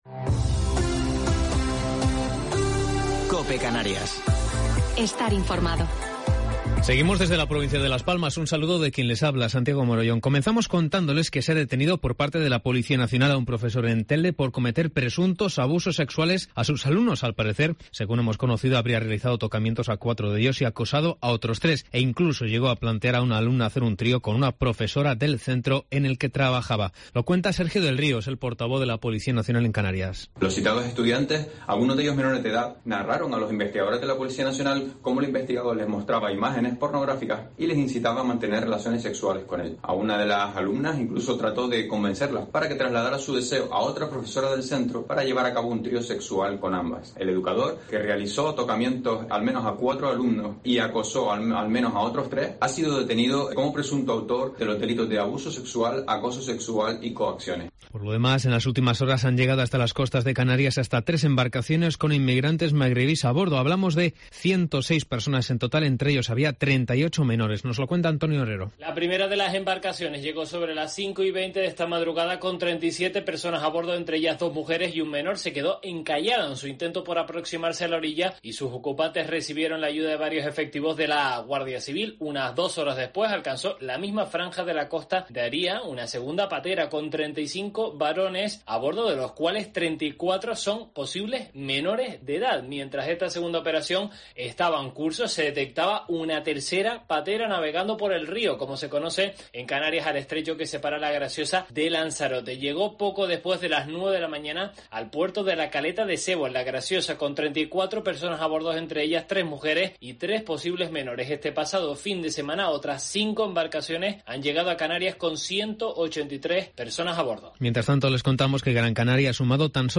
Informativo local 31 de Mayo del 2021